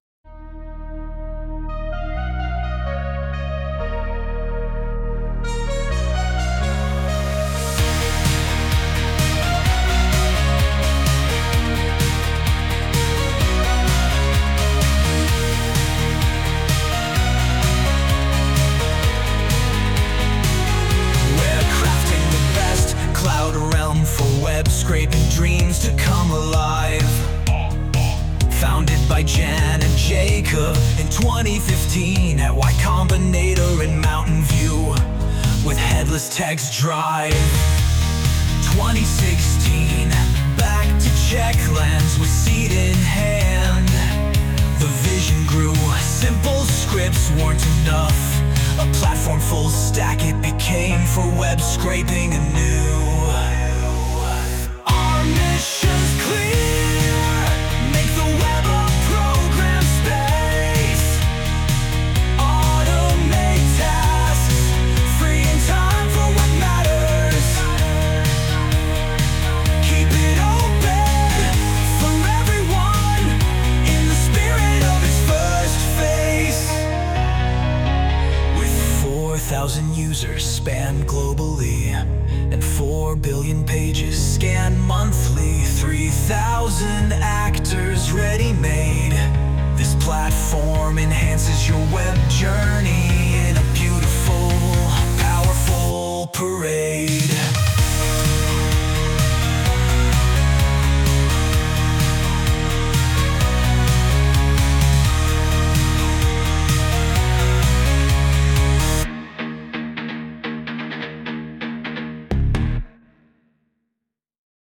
Auto-genre song – based on the Apify homepage